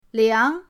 liang2.mp3